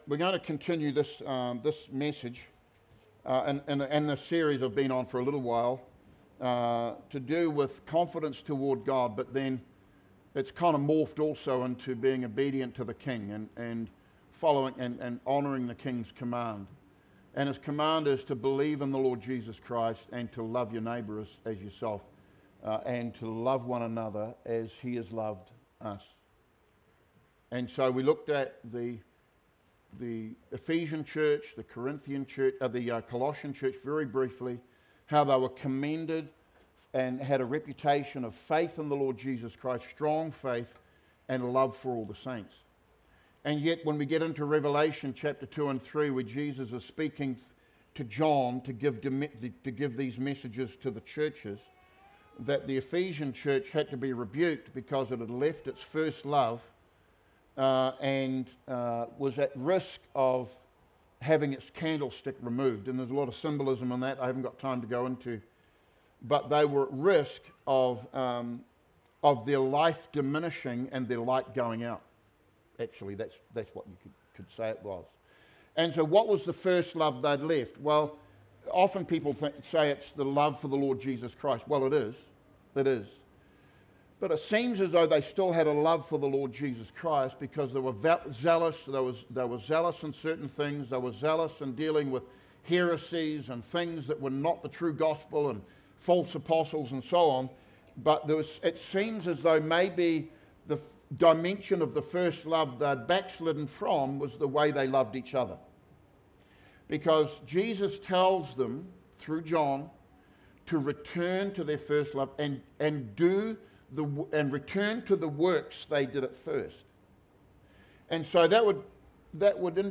Sermons | Living Waters Christian Centre
Current Sermon